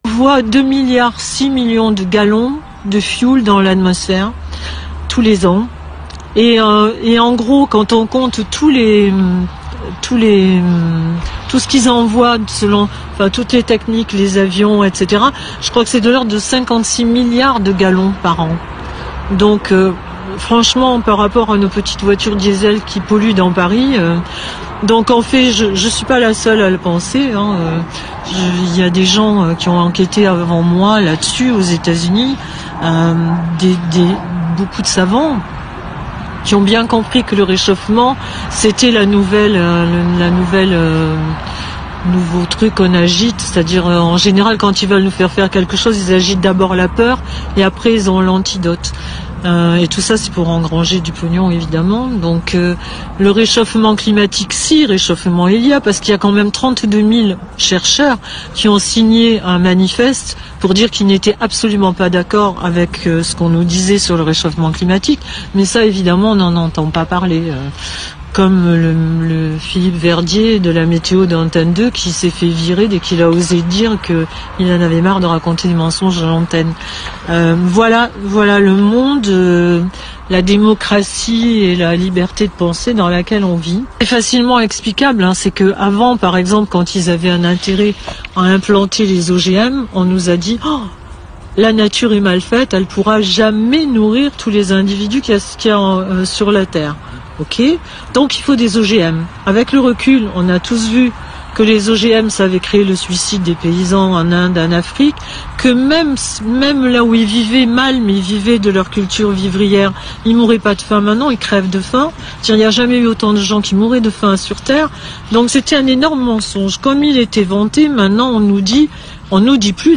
Sur Radio Electrons Libres, nous avons choisi de diffuser quelques extraits d’une interview qu’elle avait donné en 2016, quelques mois avant sa disparition.
interview-en-2016.mp3